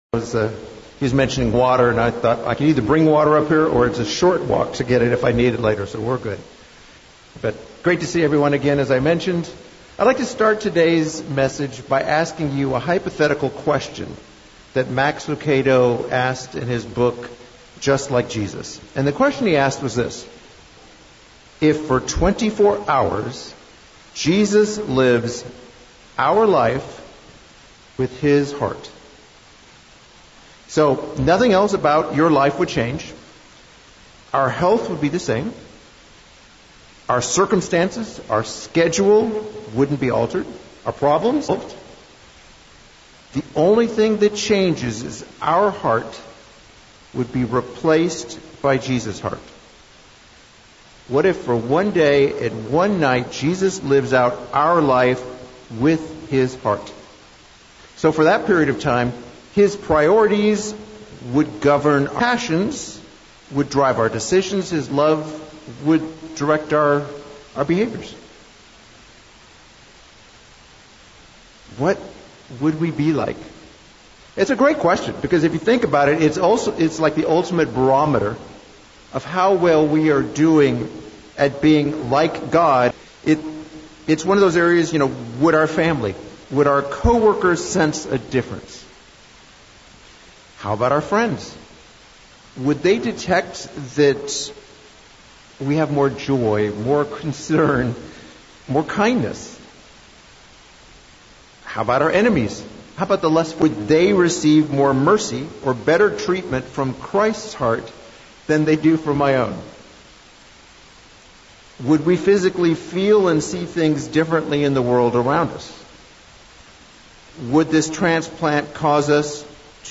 This sermon addresses five excuses we continually use but should avoid.